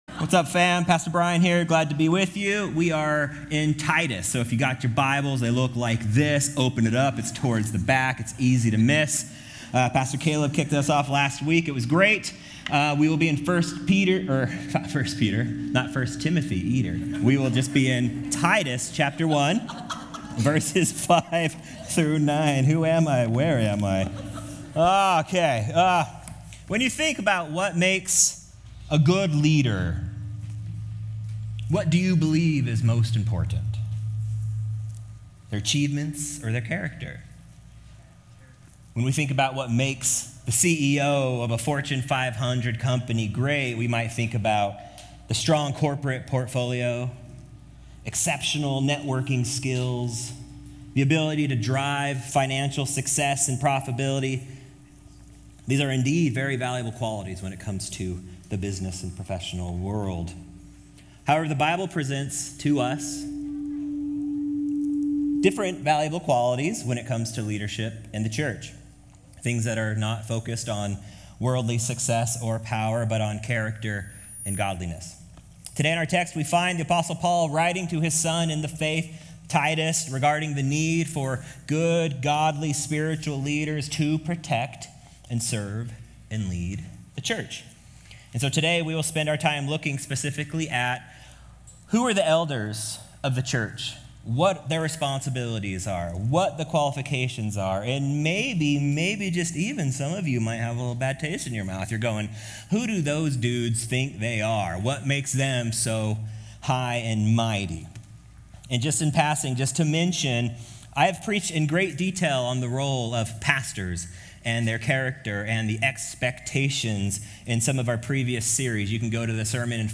In this message, we will look at the profound and challenging qualifications for Elders in the Church. We’ll also look at how these verses are to be applied to every Believer's life.